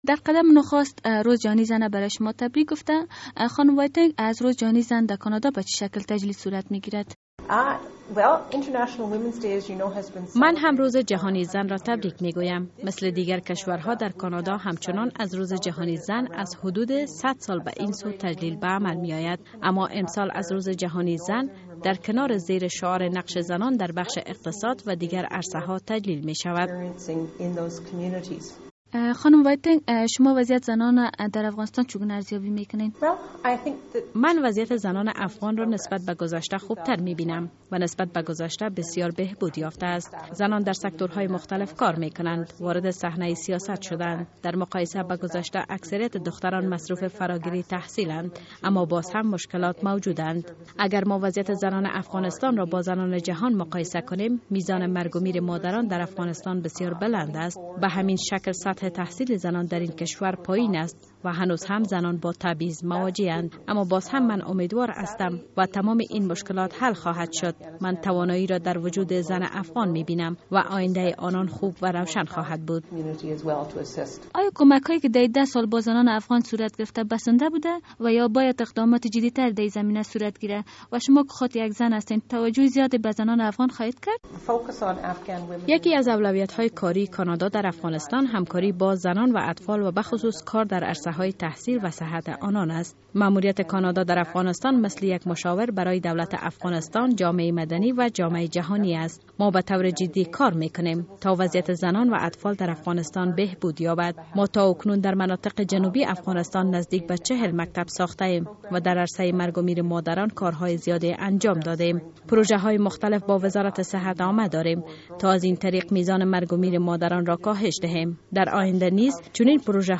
سرپرست سفارت کانادا در کابل می گوید، که آنان پس از سال 2014 میلادی نیز به همکاری هایشان با زنان افغان ادامه خواهند داد. شیلی وایتنگ به مناسبت هشت مارچ روز جهانی زن در صحبت با رادیو آزادی گفت، هنوز هم...